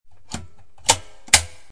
Click Metal loud One